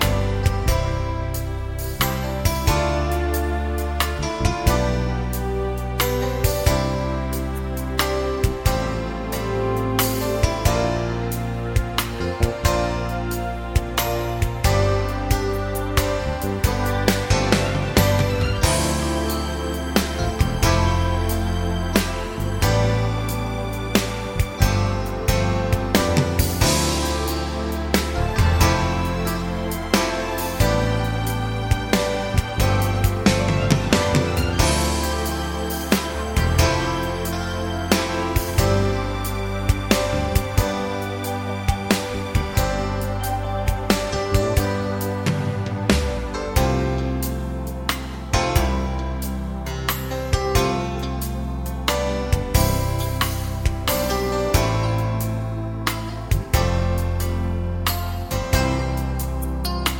no sax Soft Rock 4:24 Buy £1.50